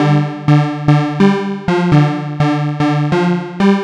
cch_synth_loop_steve_125_Dm.wav